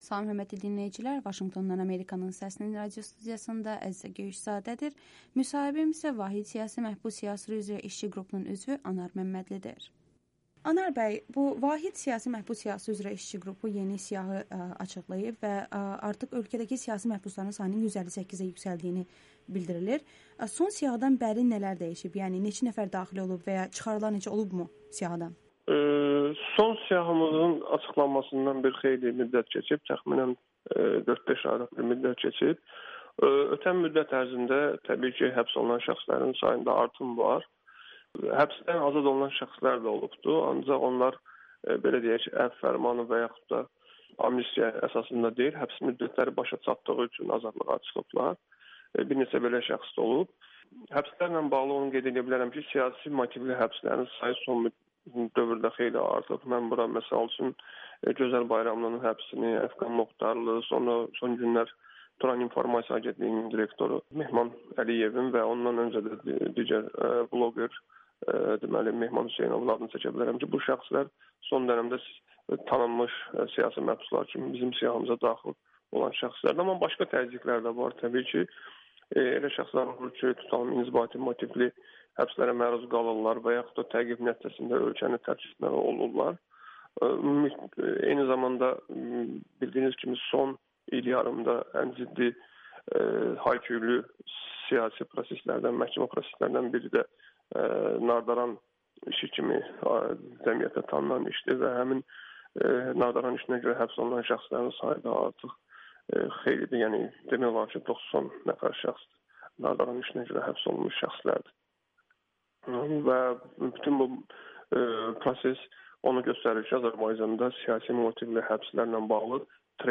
"Azərbaycan hökumətinin Qərbin ciddi təpkilərinə məruz qalmaması bağışlanmazdır" [Audio-Müsahibə]